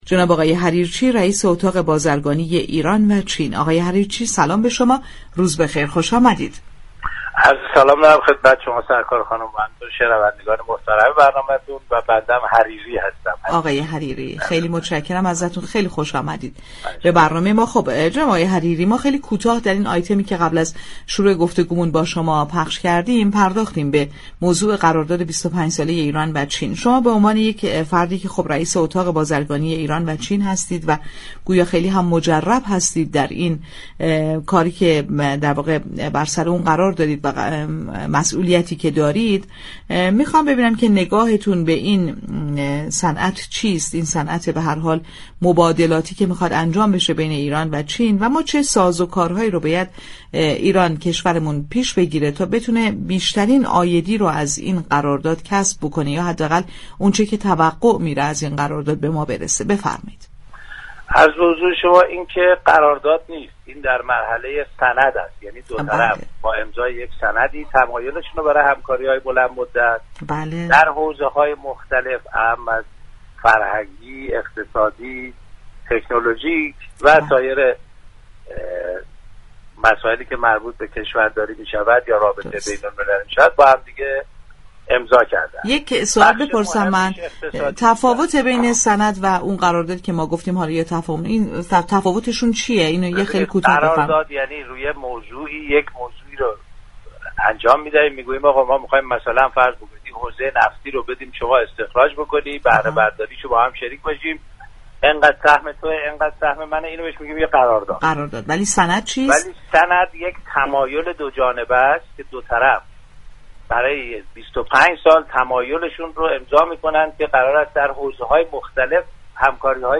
در گفتگو با بازار تهران رادیو تهران